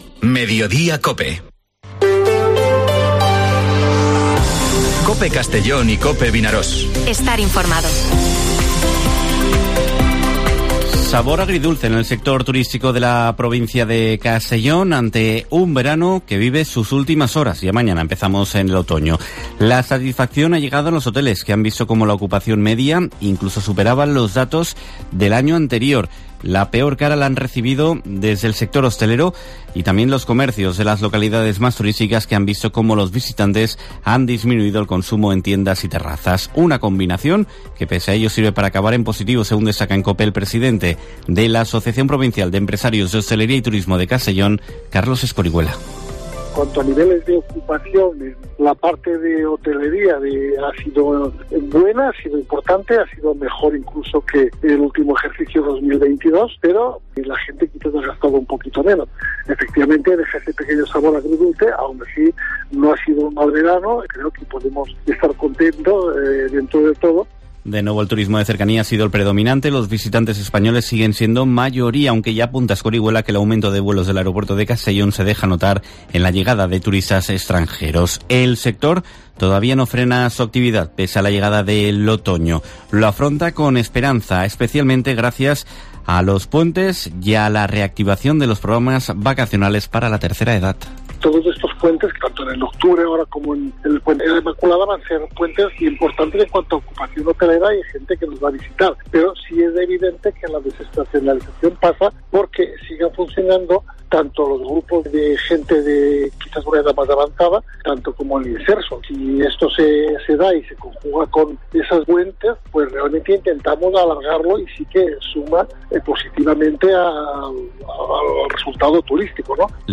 Informativo Mediodía COPE en la provincia de Castellón (22/09/2023)